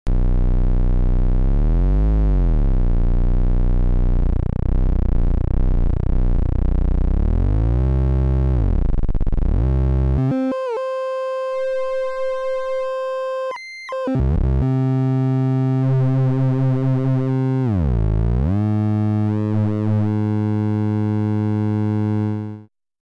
To me it sounds extremely muffled, as if there’s already a filter on it.